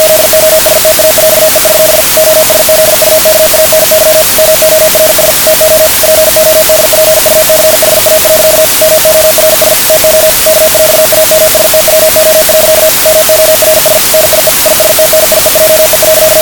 Z tego pliku audio wydobywają się jakieś dziwne piski, dowiedz się, o co z nimi chodzi i zdobądź flagę.